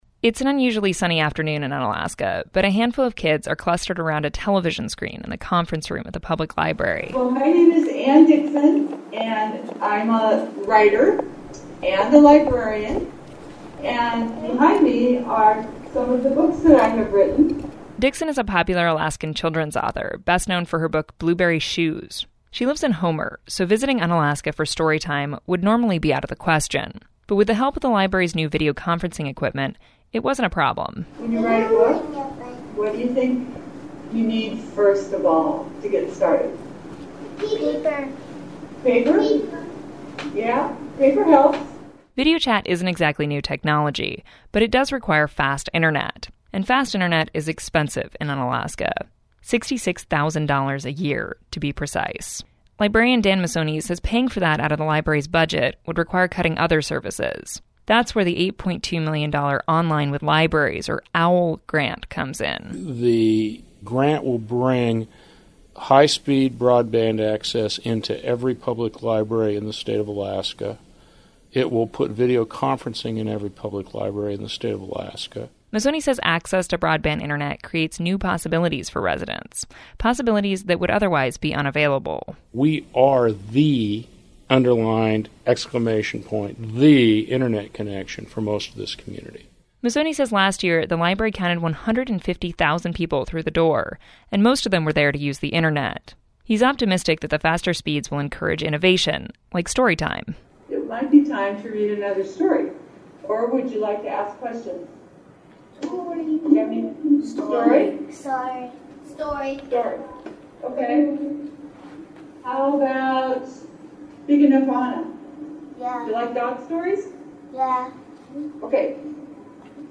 On an usually sunny afternoon in Unalaska, a handful of kids are clustered around a television screen in the conference room at the public library. A woman introduces herself.